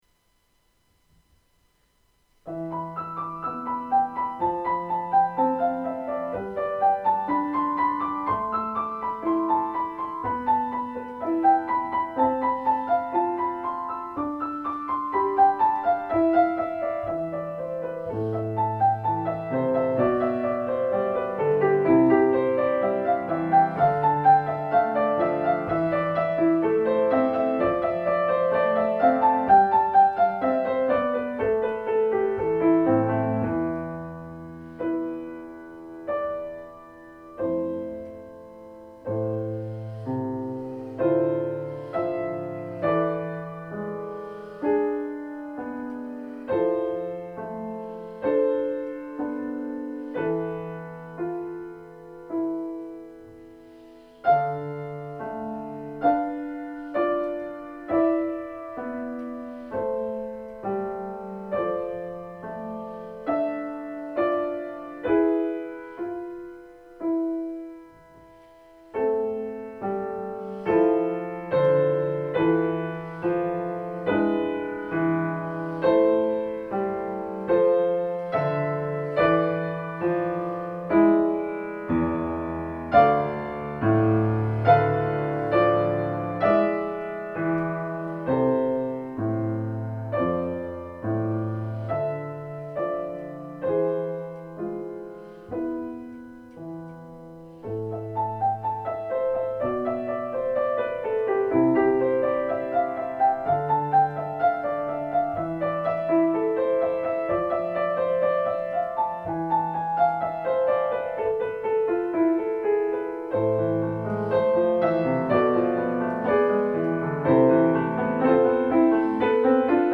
Deze ervaringen hebben me geïnspireerd om dit meerdelige werk te schrijven, het zijn sfeerbeelden in meditterane sfeer.